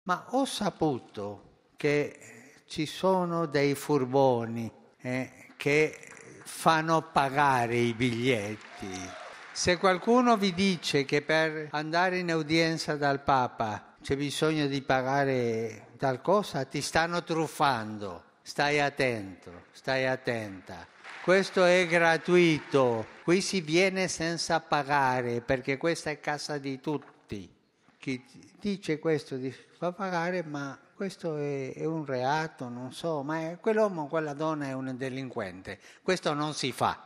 E al termine dell’udienza generale, dopo i saluti ai fedeli di lingua italiana, il Papa ha tirato fuori da una busta un biglietto rosso e ha ricordato che per entrare alle udienze i biglietti sono totalmente gratuiti.